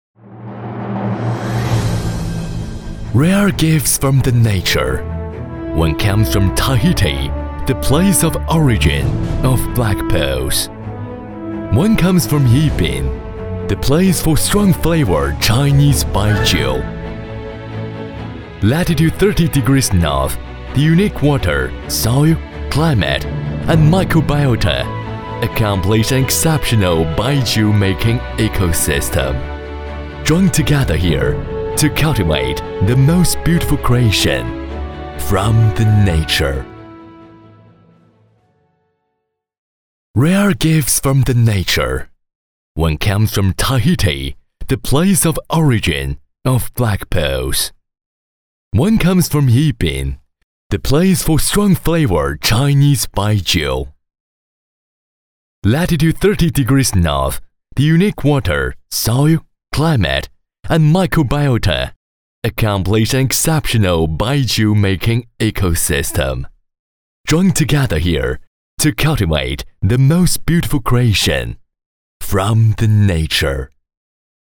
男43号配音师
声音庄重、浑厚、大气，擅长中英双语。
英文-男43-【大气稳重】五粮液.mp3